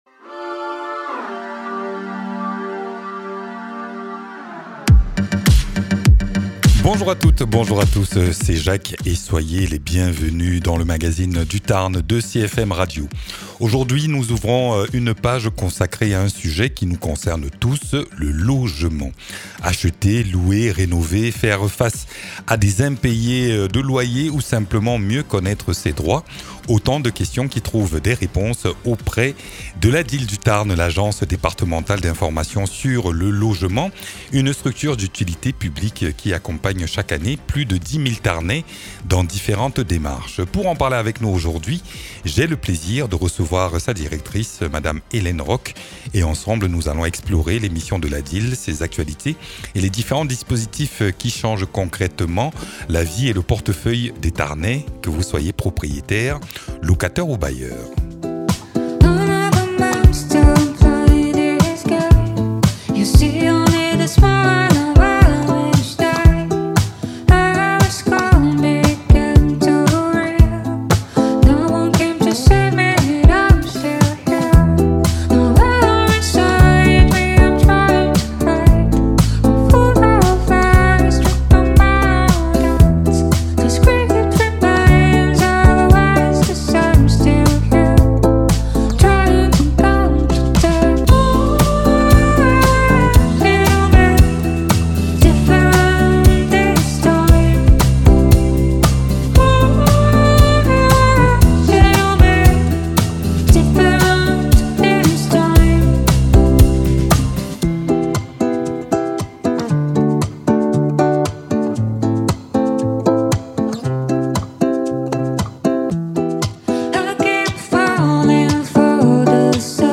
Conseils juridiques, dispositifs d’aide, nouveautés réglementaires : on fait le point sur tout ce qui change et tout ce qui peut vous simplifier la vie. Une émission à écouter pour rester à jour sur l’actualité du logement dans le Tarn.